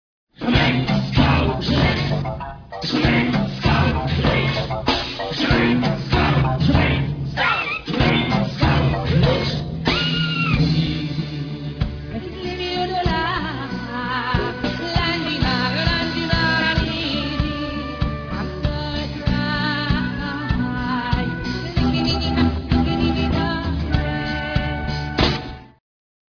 Концерт с оркестром в студии на Шаболовке(1996)